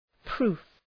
proof Προφορά
{pru:f}